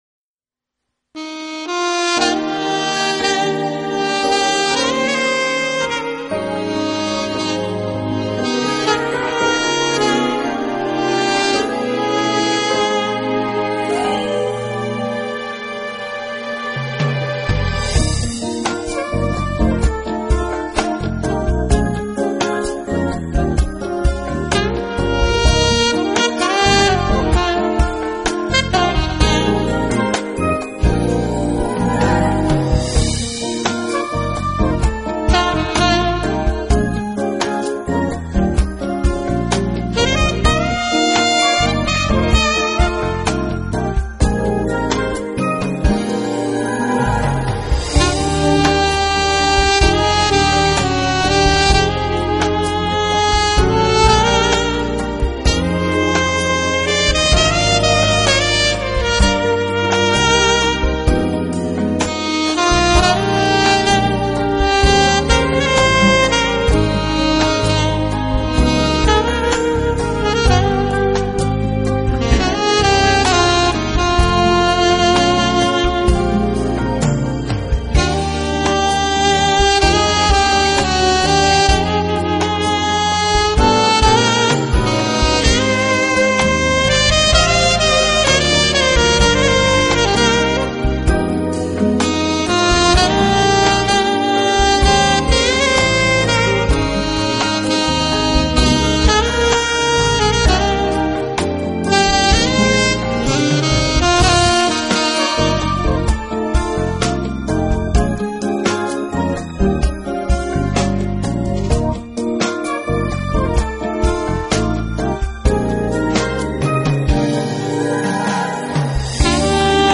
【圣诞萨克斯】
音乐类型：KPOP
时而灵动，时而欢快的风格，在圣诞节这个寒冷的季节里，用音乐诠释舒畅与欢